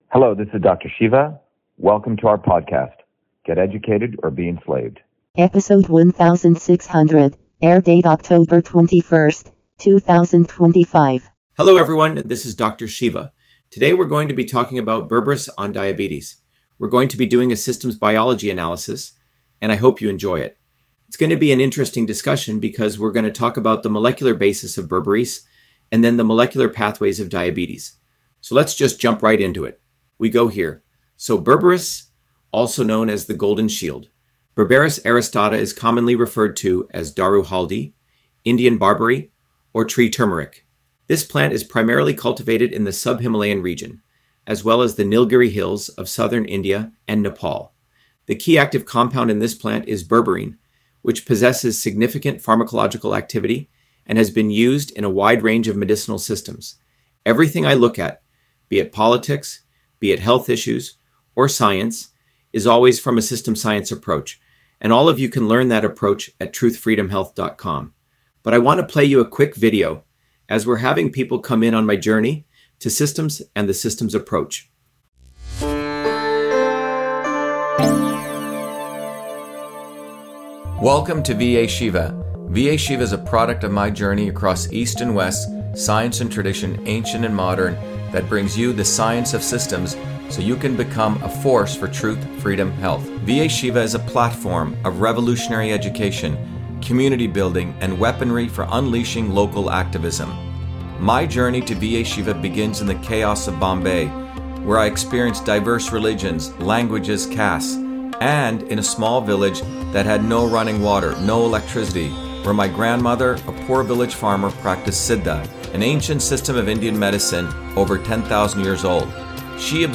In this interview, Dr.SHIVA Ayyadurai, MIT PhD, Inventor of Email, Scientist, Engineer and Candidate for President, Talks about Berberis on Diabetes: A Whole Systems Approach